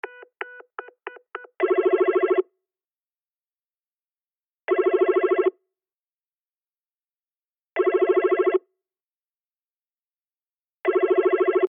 / G｜音を出すもの / G-01 機器_電話
電話コール音携帯から再生
プープー